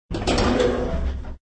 SElevatorDoorOpen.ogg